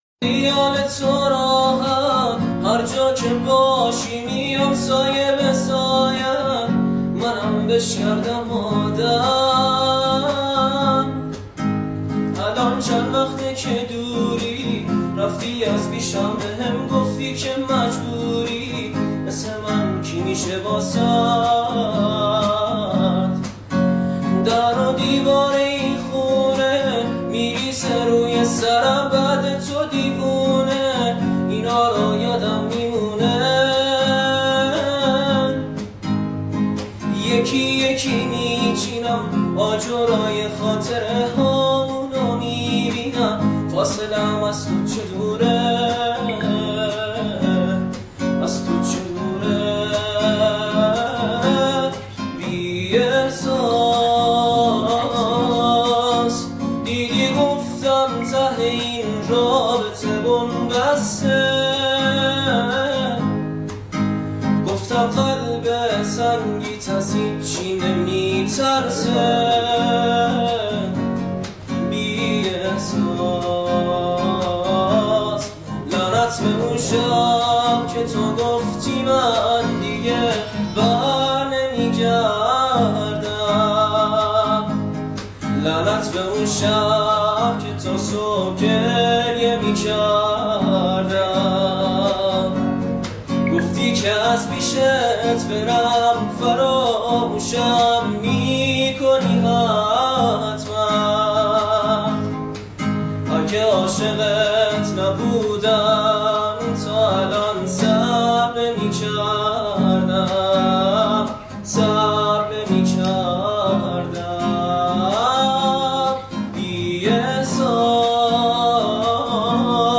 با گیتار صدای مرد